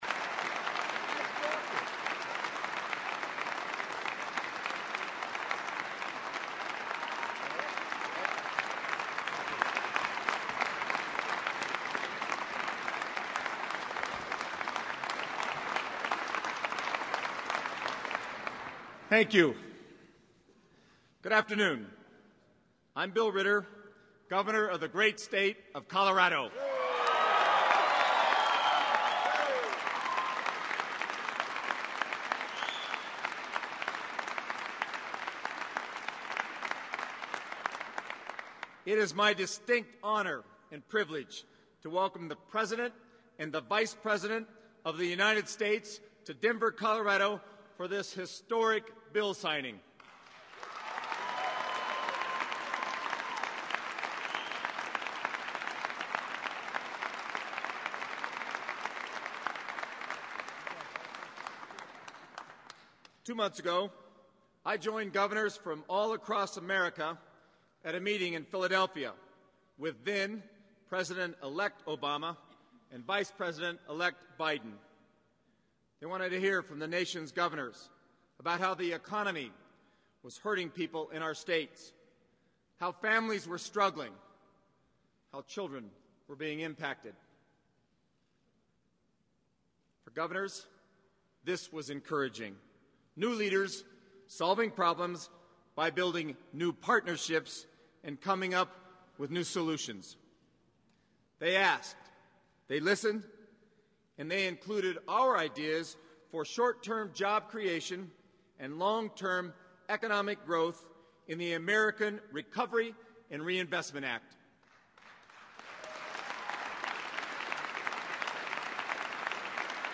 Governor of Colorado Bill Ritter (D) introduces the Colorado-based solar electric company, Namaste. Vice President Joe Biden discusses the uses of the stimulus package on the U.S. economy of the future.
President Obama speaks on the work the stimulus package will create including the improvement of roads, education, and the digitization of health care records.
Broadcast on C-SPAN, Feb. 17, 2009.